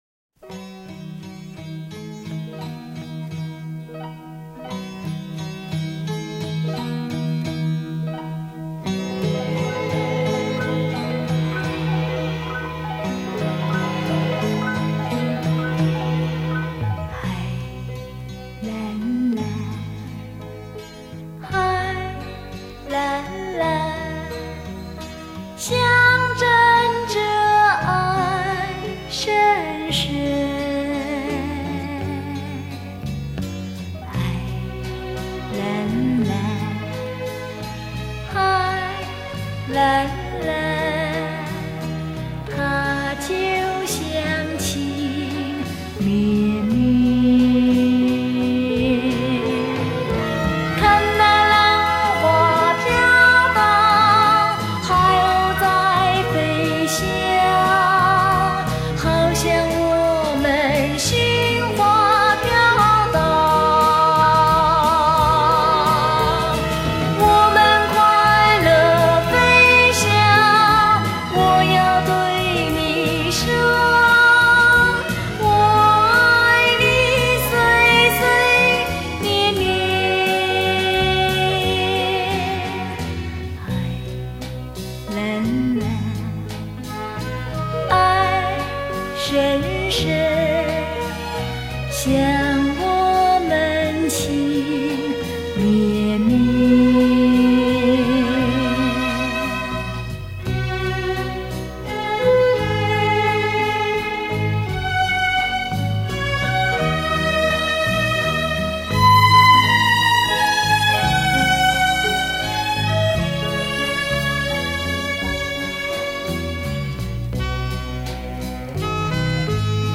三厅文艺电影歌曲
刘家昌式电影情歌
原曲原唱电影原声带